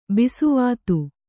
♪ bisuvātu